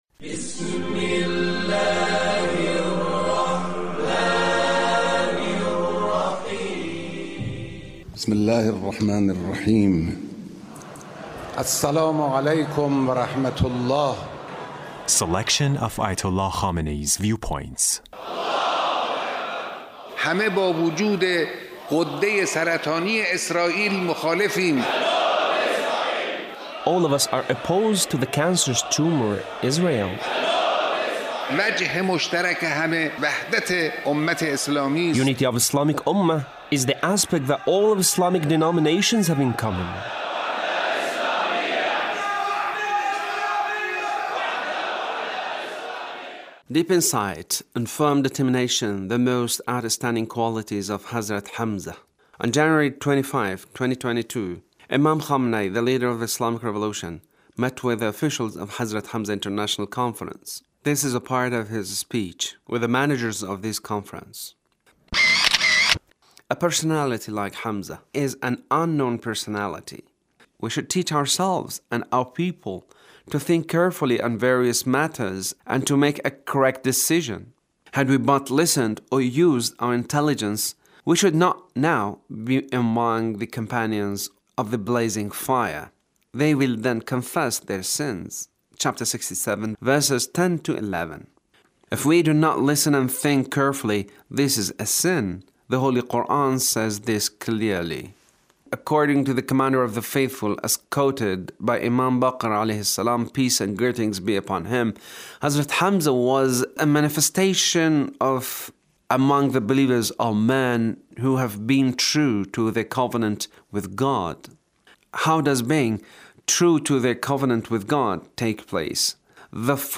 Leader's speech